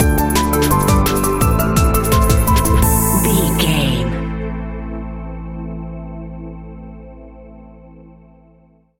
Aeolian/Minor
Fast
futuristic
frantic
driving
energetic
hypnotic
dark
drum machine
electric piano
synthesiser
sub bass